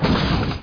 1 channel
BOOM3.mp3